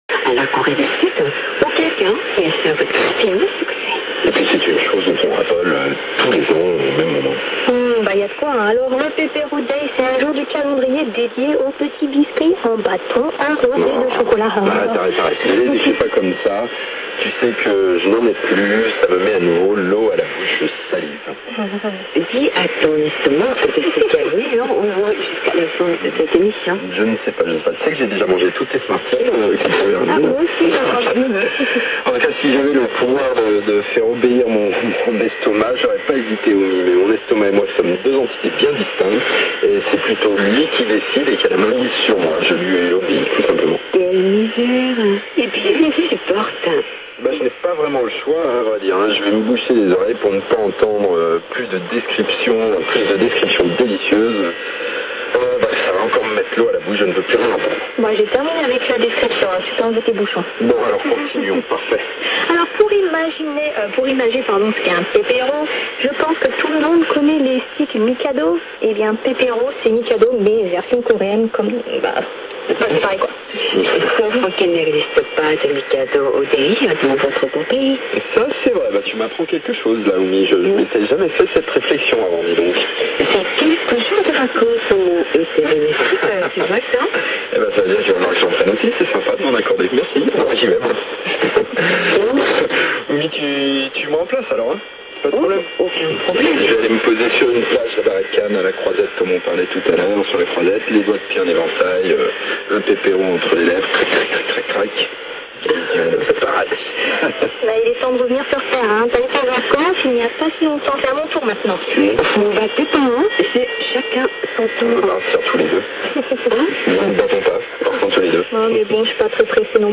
Enregistrements effectués sur le portatif et son antenne télescopique, des incidents constatés lors des transmissions radio de la KBS